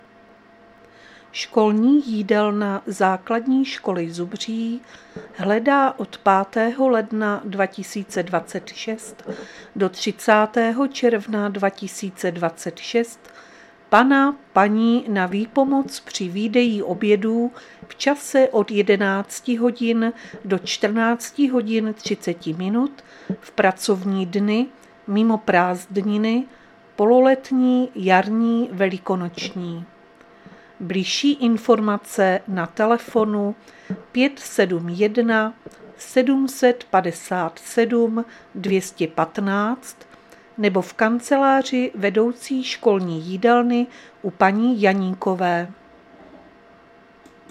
Záznam hlášení místního rozhlasu 3.12.2025